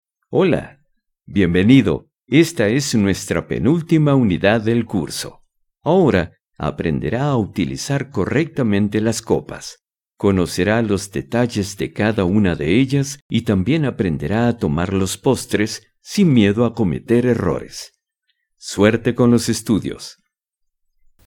Neutral Spanish Voice Over and Dubbing Actor.
Sprechprobe: eLearning (Muttersprache):
Wide variety of character voices in spanish and english.